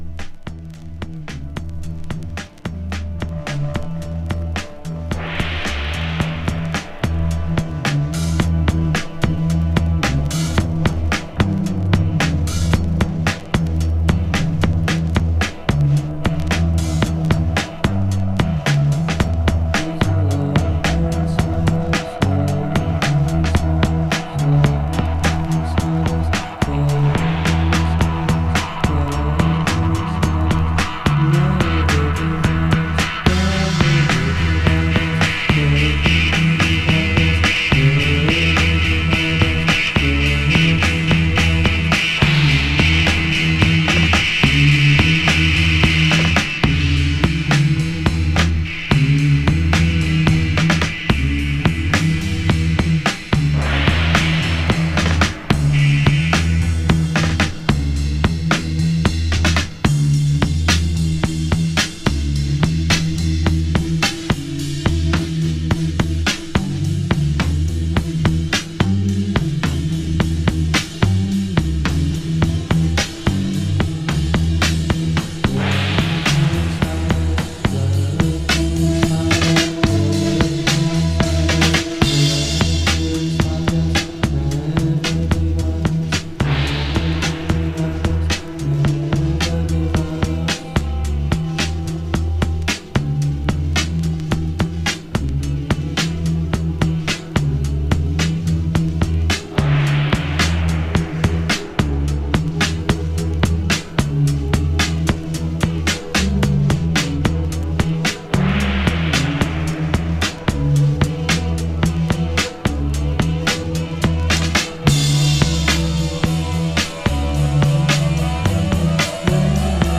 冷たく響くドラムマシン、深いベースライン、ミニマルなシンセ